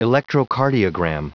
Prononciation audio / Fichier audio de ELECTROCARDIOGRAM en anglais
Prononciation du mot electrocardiogram en anglais (fichier audio)